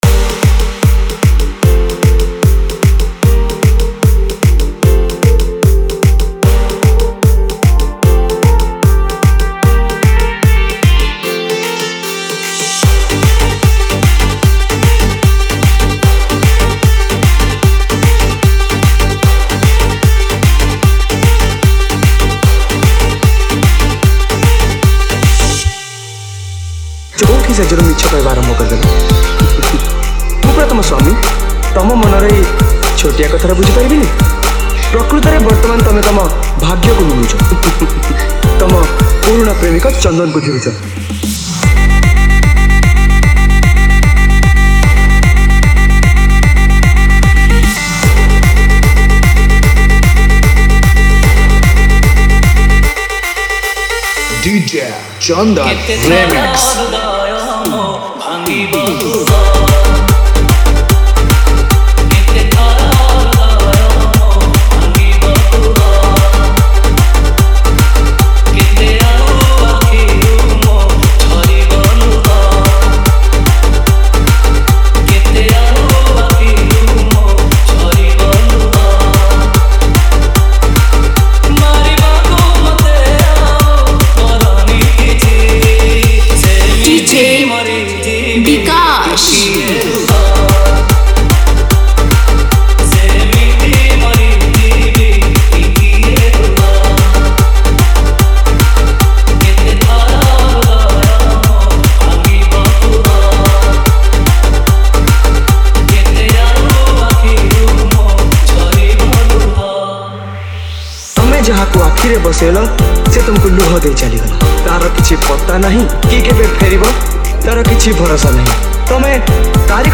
Romantic Love Dj Remix Songs Download